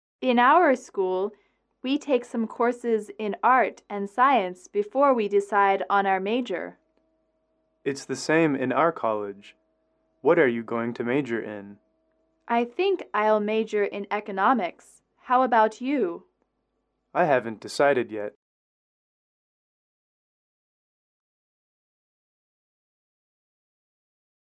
英语口语情景短对话06-4：主修学科